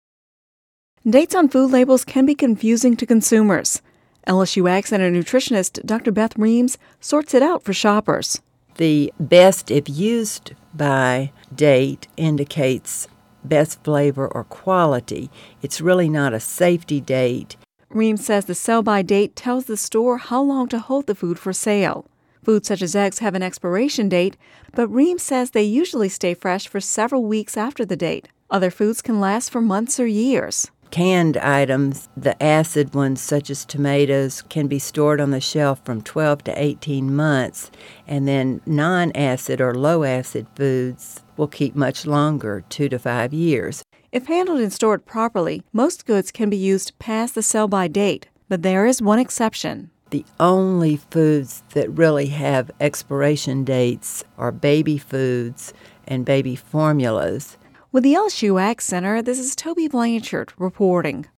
(Radio News 10/04/10) Dates on food labels can be confusing to consumers.